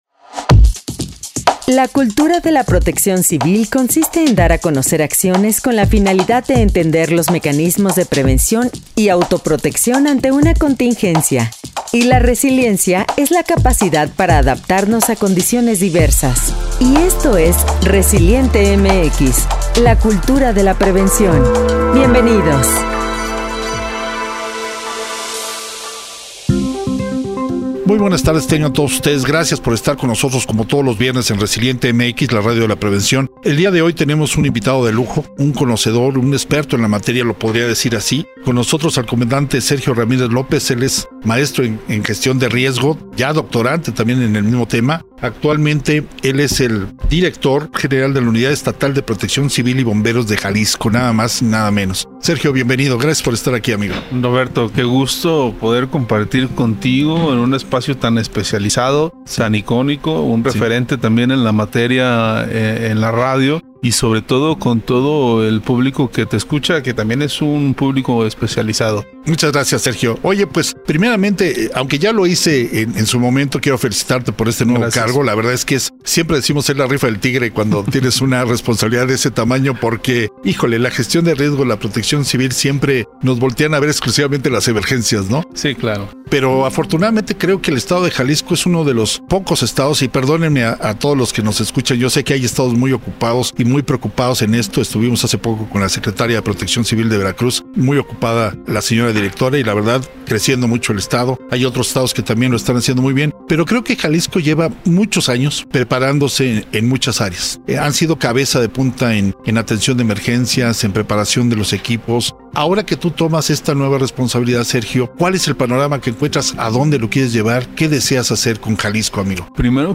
Entrevista con el Comandante Sergio Ramírez, quien es maestro y director de la unidad estatal de protección civil y bomberos del Estado de Jalisco. Nos comenta sobre los retos y el camino a seguir en la protección civil y la gestión de riesgo ante este nuevo cargo para los próximos años. En temas como la formación académica profesional en el ejercicio del mando y su responsabilidad.